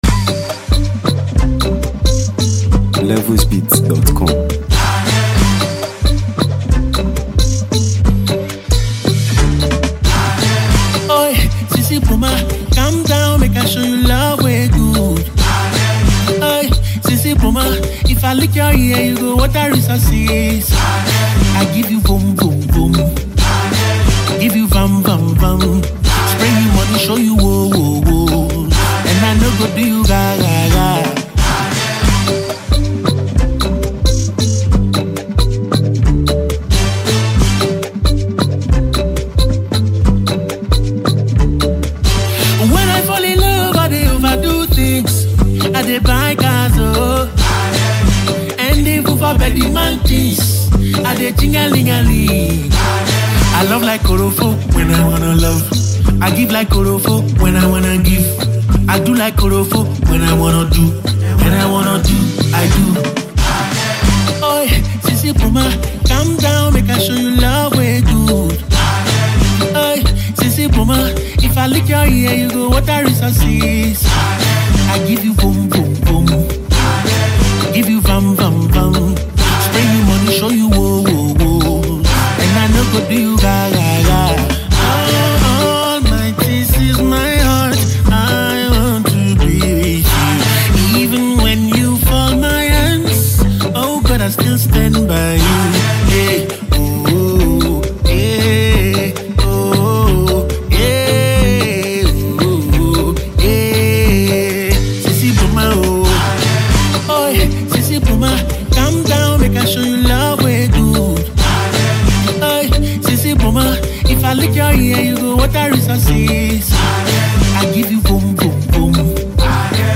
a smooth and emotionally charged performance
an infectious Afro-pop rhythm